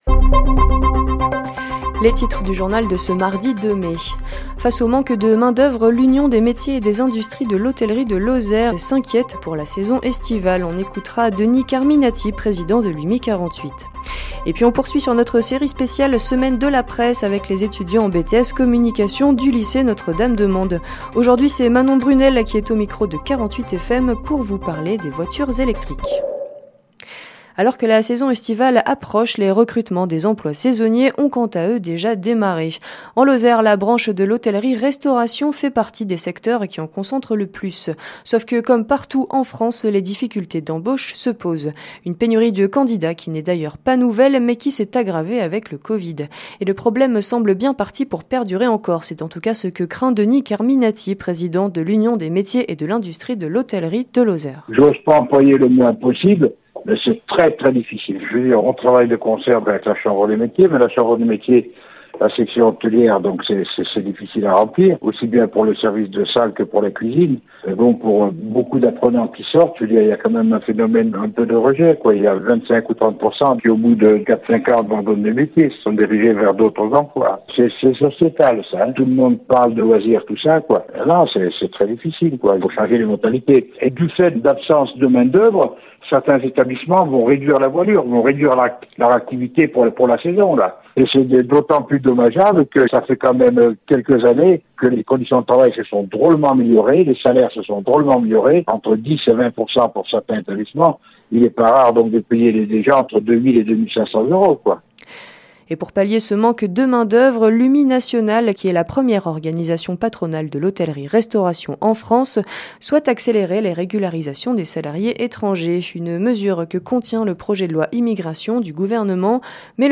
Les informations locales
Le journal sur 48FM